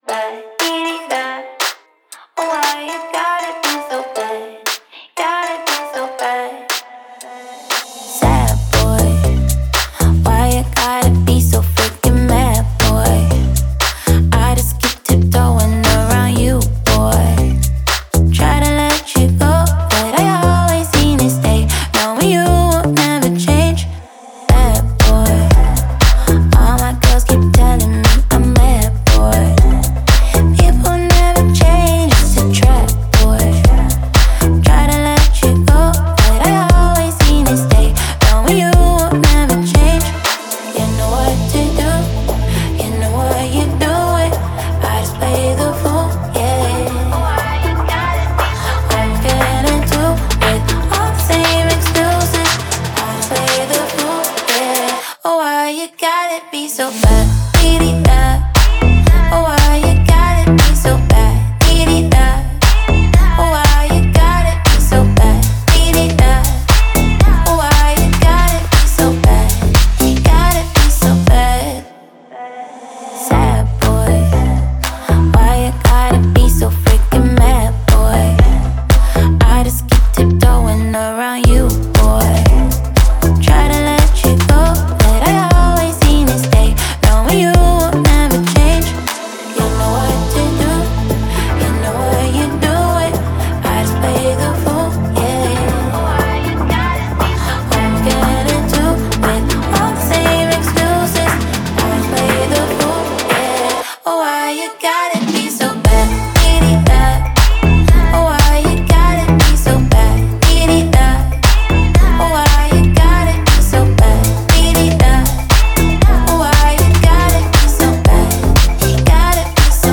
это трек в жанре электронной музыки с элементами попа